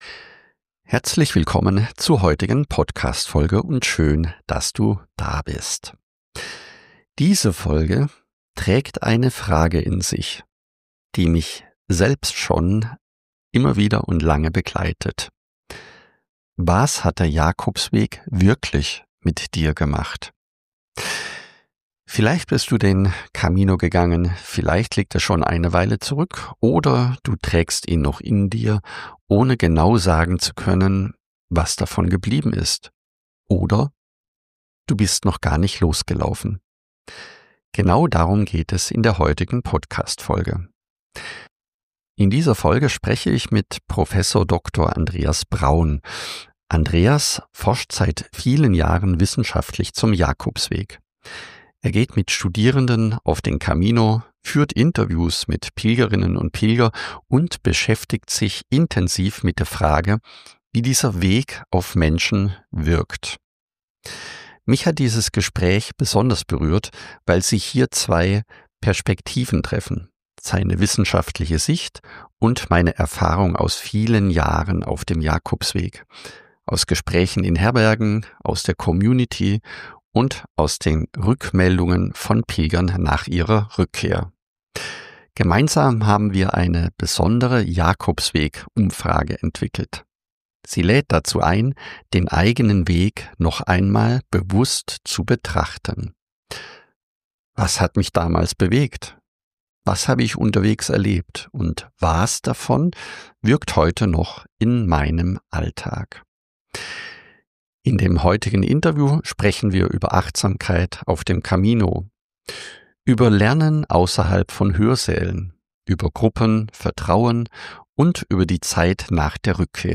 Beschreibung vor 3 Monaten Im Gespräch